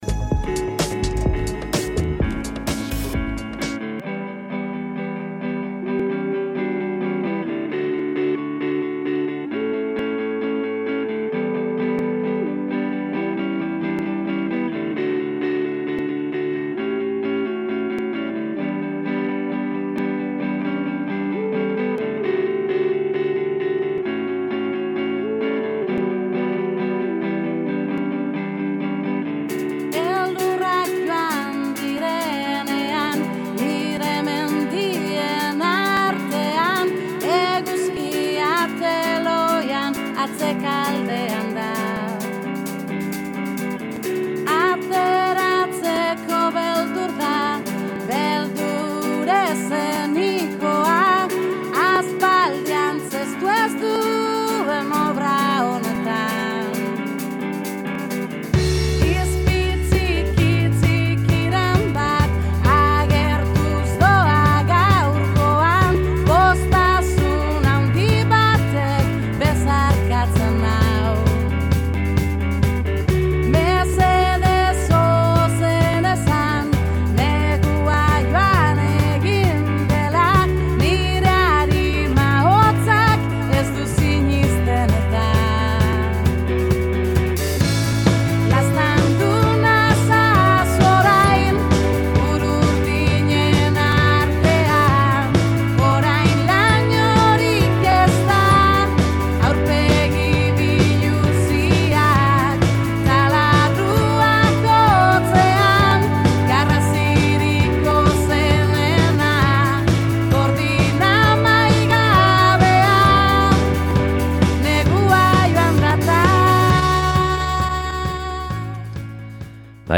INKESTA